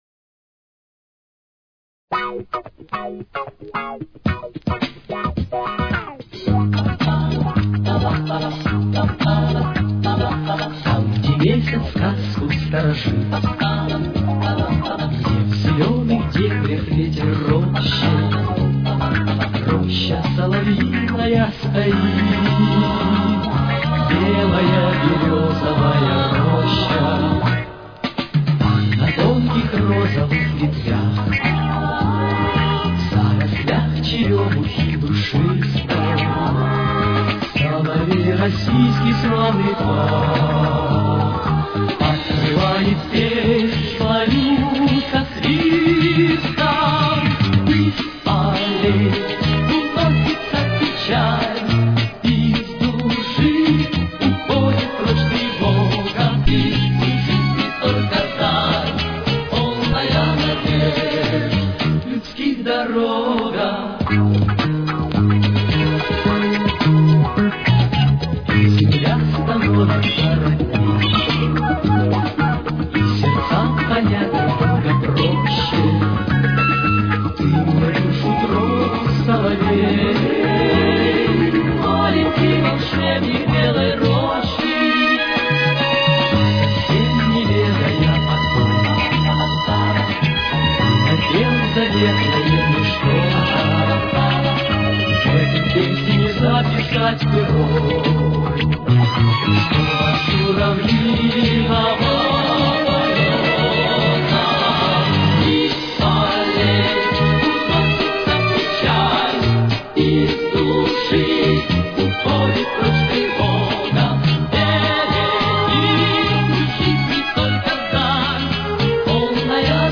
Тональность: Соль-диез минор. Темп: 111.